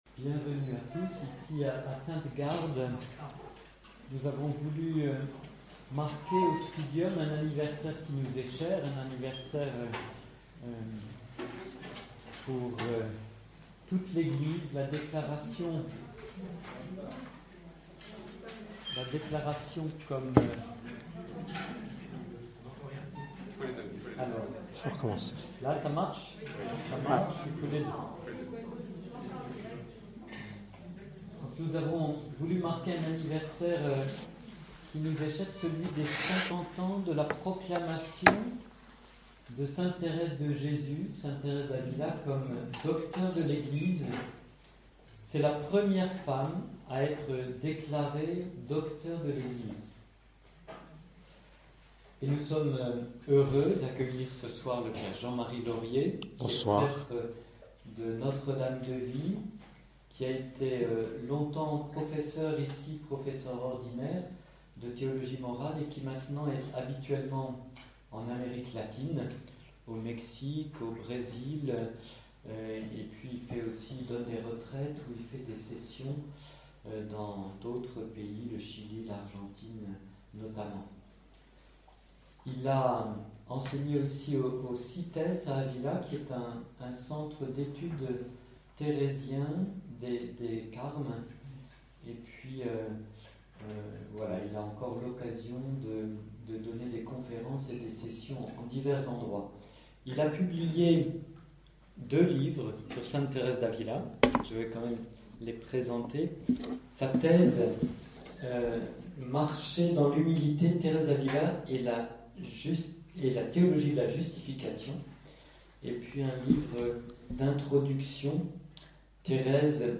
Conférence : Aimer et servir l’Église de son temps: le témoignage de sainte Thérèse d'Avila | Enseignement en ligne | Studium de Notre Dame de Vie